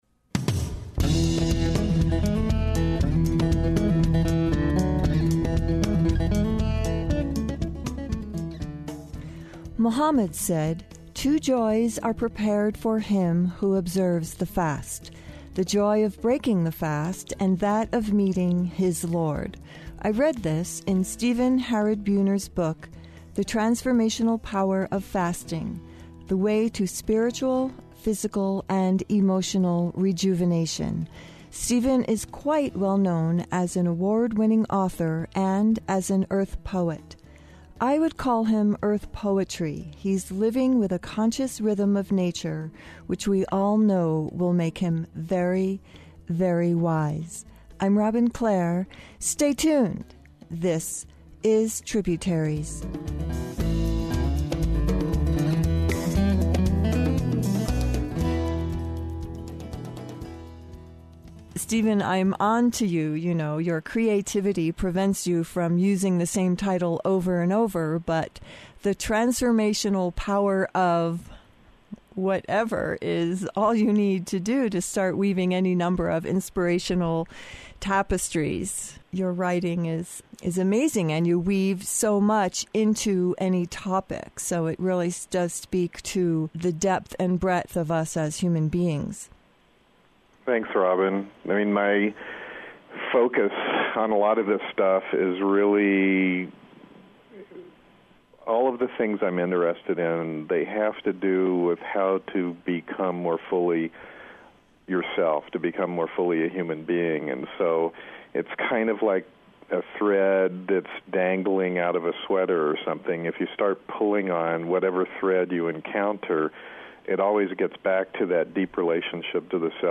Today’s discussion; The Transformational Power of Fasting.